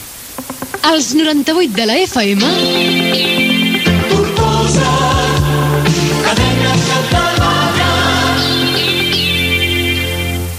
Indicatiu de l'emissora amb la frerqüència
FM